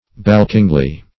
balkingly - definition of balkingly - synonyms, pronunciation, spelling from Free Dictionary Search Result for " balkingly" : The Collaborative International Dictionary of English v.0.48: Balkingly \Balk"ing*ly\, adv. In a manner to balk or frustrate.